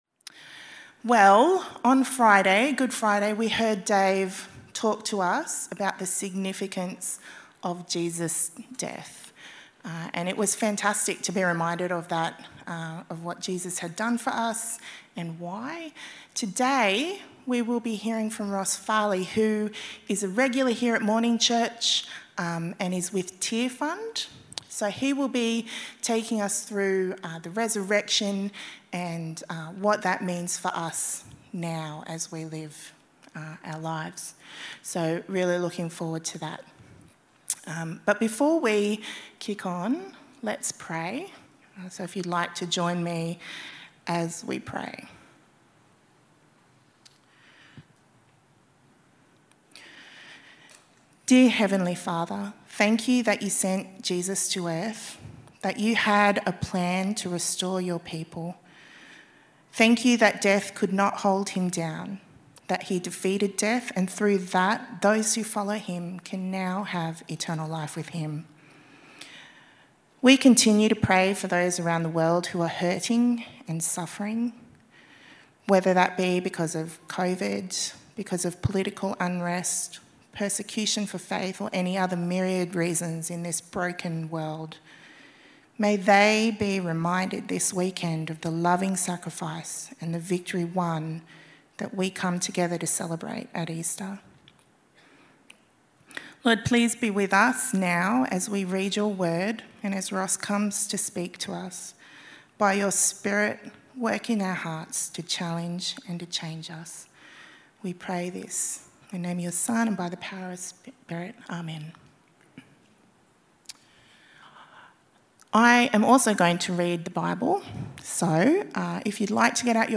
TalkCalledToLiveNow.mp3